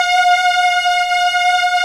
Index of /90_sSampleCDs/Roland LCDP13 String Sections/STR_Combos 2/CMB_Mellow Pad
STR SLOW V06.wav